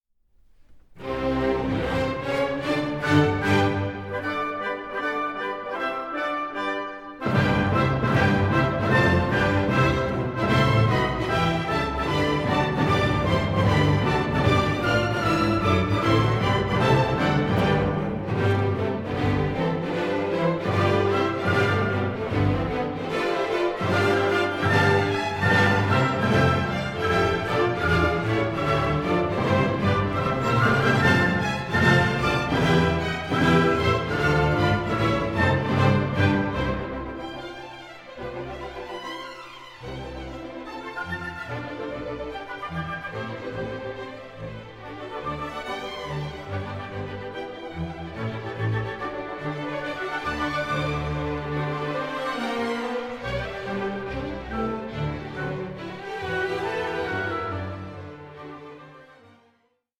Allegro molto vivace 8:17